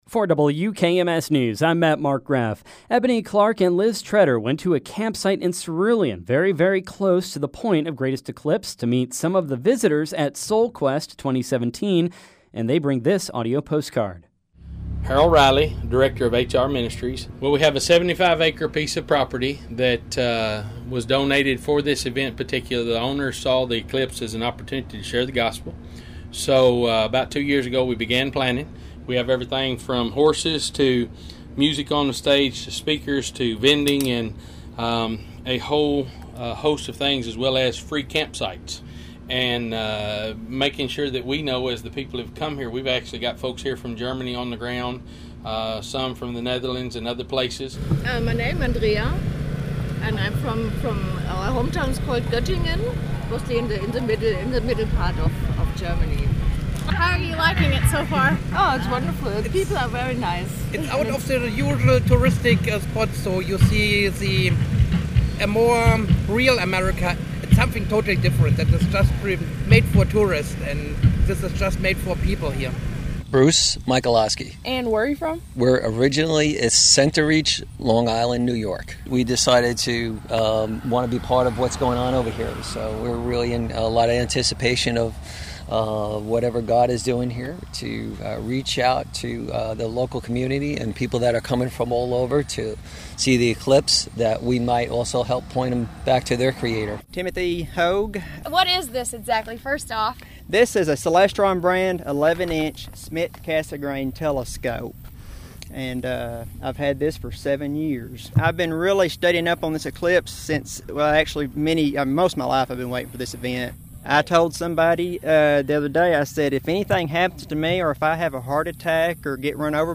Sounds from Cerulean: Eclipse Viewers Gather at SolQuest
The site has a street fair, some outdoor shows and concerts, and a campground for visitors to stargaze.